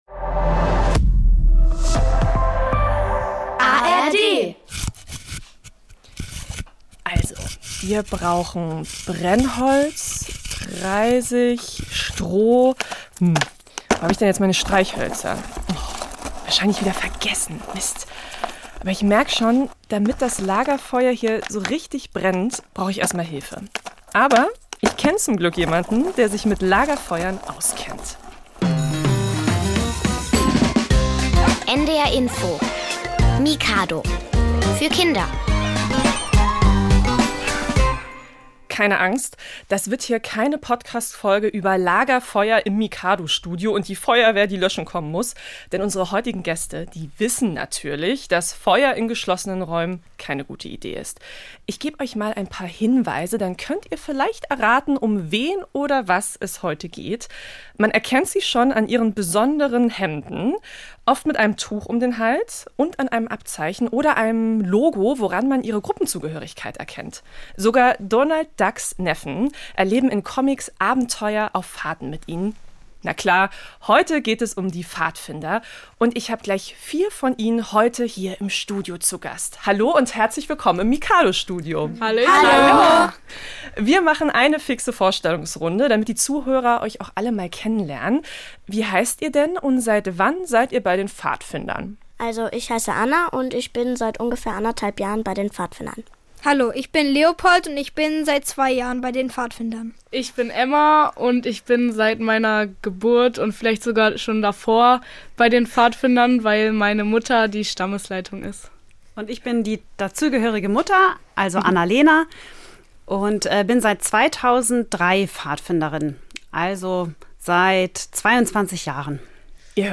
Bei uns kommen Kinder und Experten zu Wort, es gibt Rätsel, Witze, Reportagen, Buch- und Basteltipps, Experimente und Musik.